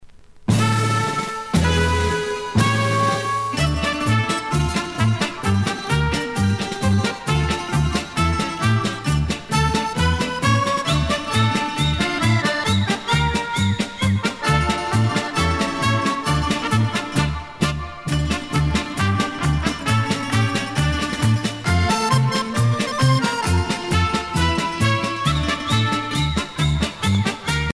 danse : kazatchok